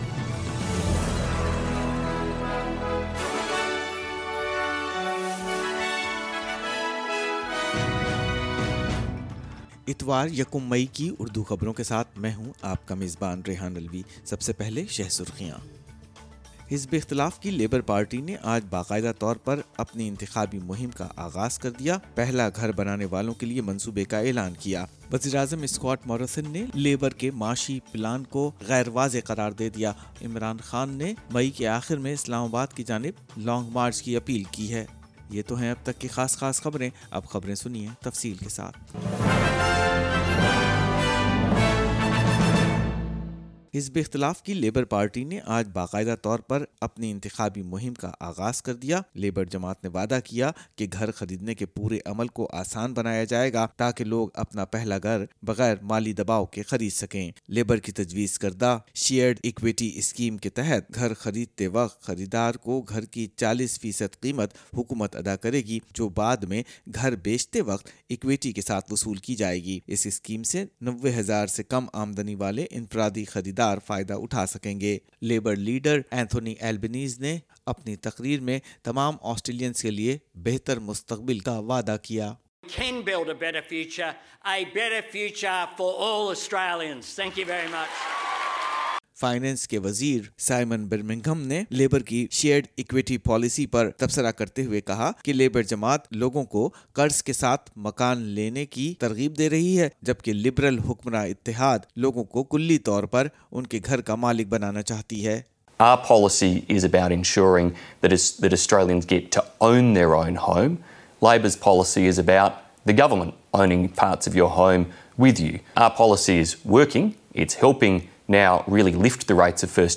Opposition Labor party launches its official election campaign - Urdu News Sunday 1 May 2022